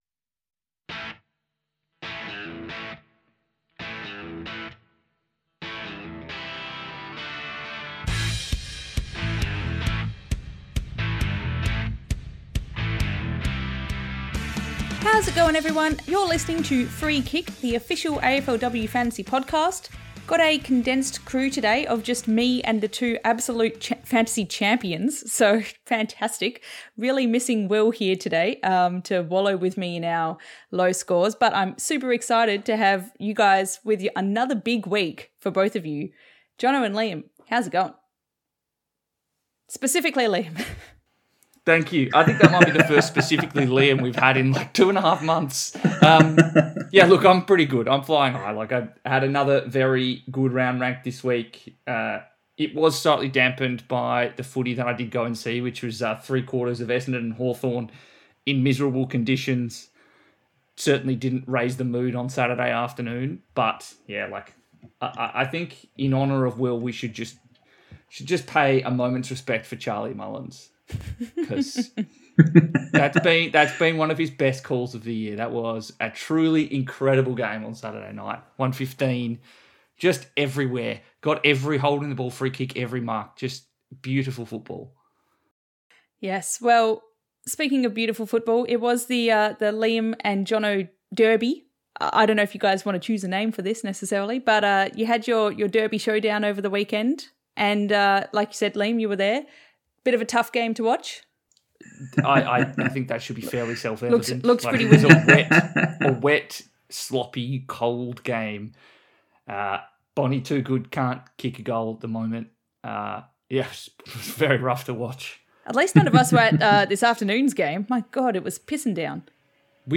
rain sounds of the Melbourne night storm